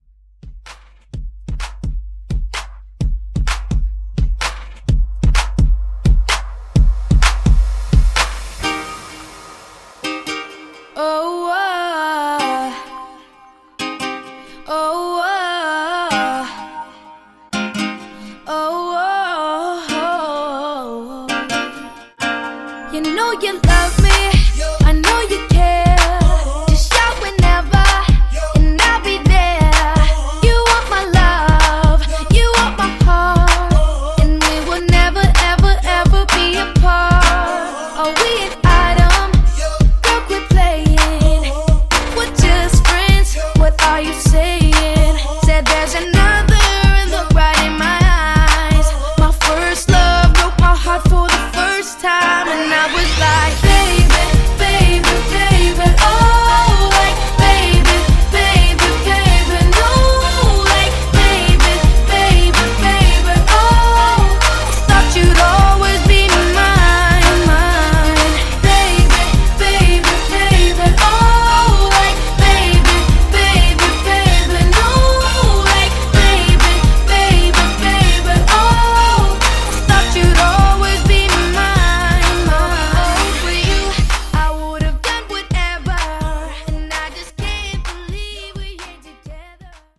Genre: DANCE
Clean BPM: 124 Time